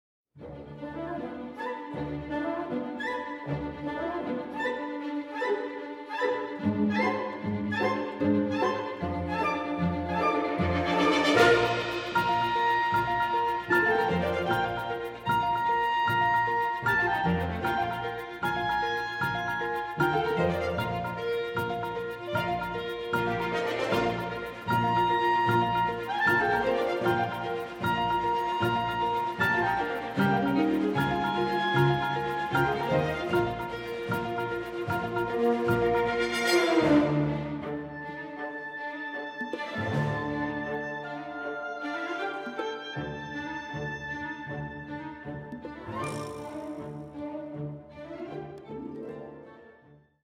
classical-ambience.mp3